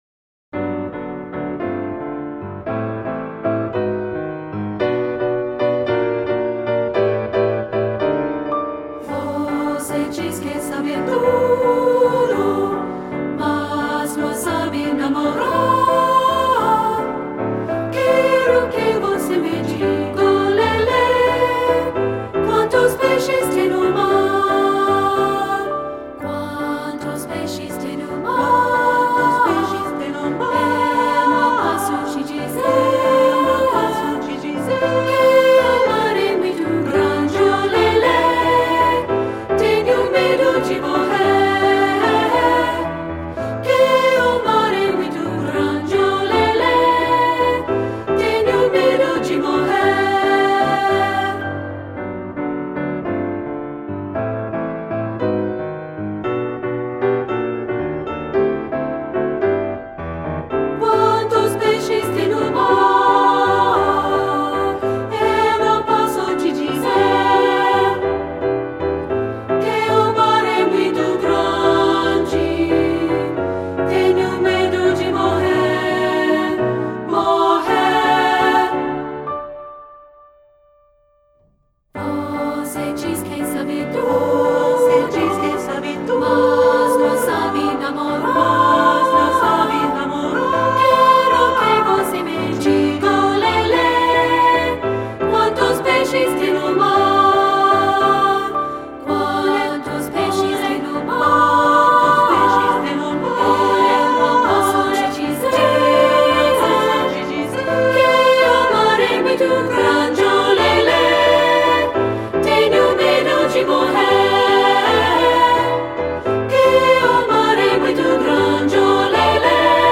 Brazilian Folk Song